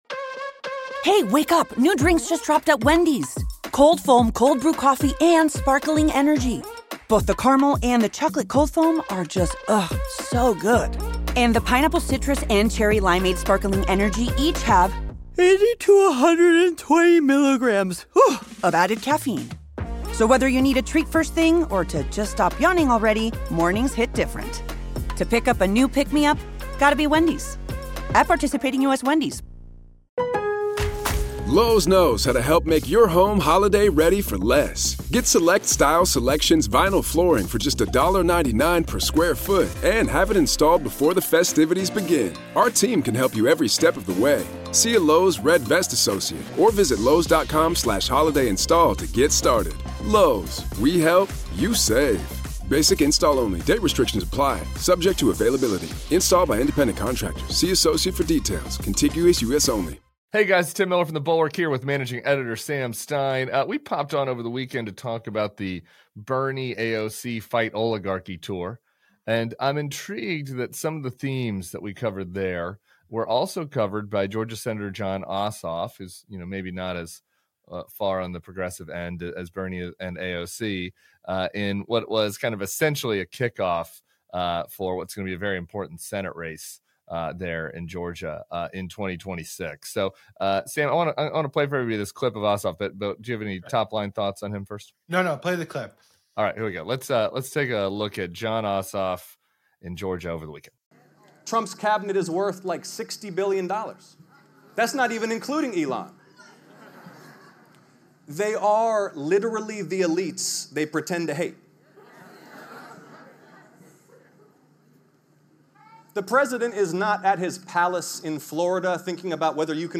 Tim Miller and Sam Stein discuss.